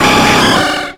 Cri de Poissoroy dans Pokémon X et Y.